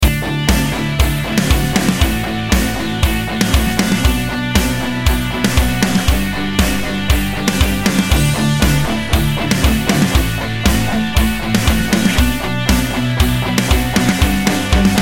Inspirational, Rock